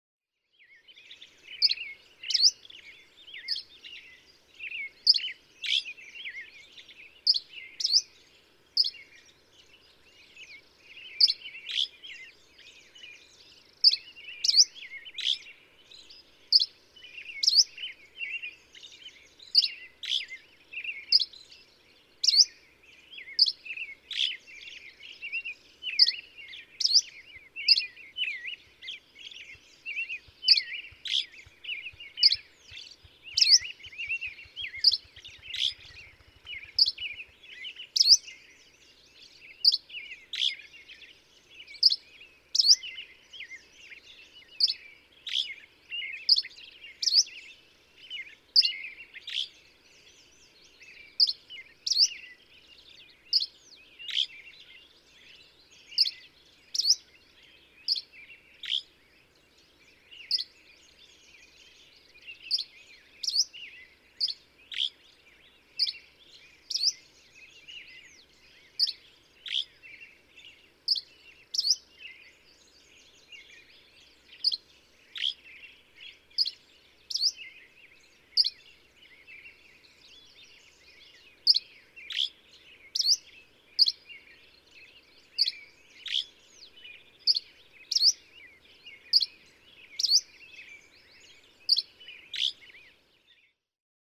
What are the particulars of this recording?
Lava Hot Springs, Idaho.